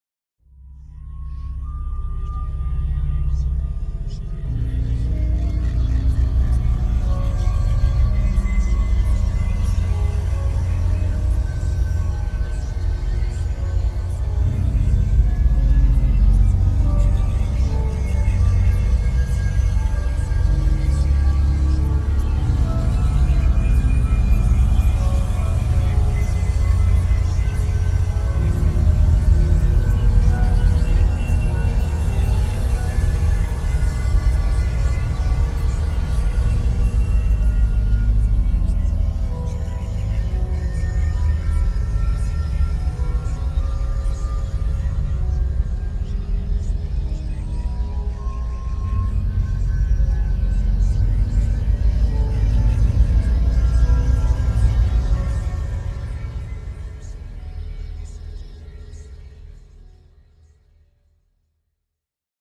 Creepy Whispering Background Sound Effect
Description: Creepy whispering horror background sound effect with eerie ambient textures. A dark and terrifying background sound with whispering elements, perfect for creating or enhancing an anxious or dramatic atmosphere.
Creepy-whispering-background-sound-effect.mp3